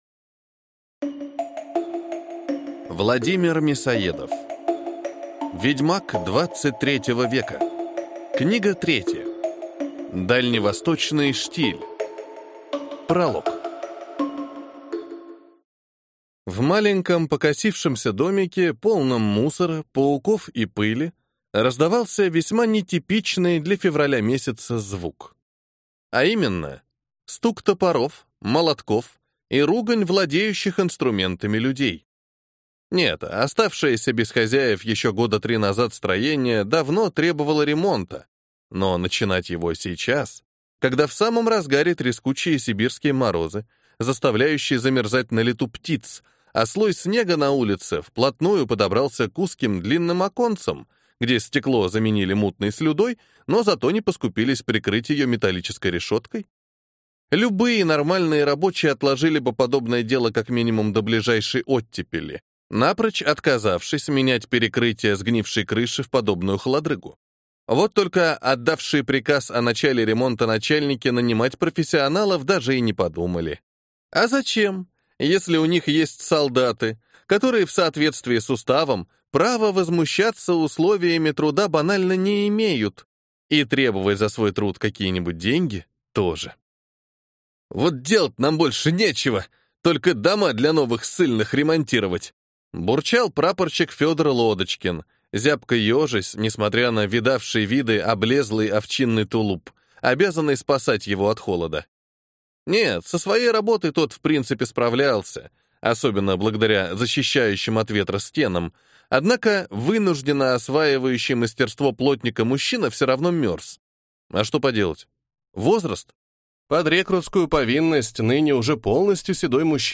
Аудиокнига Дальневосточный штиль | Библиотека аудиокниг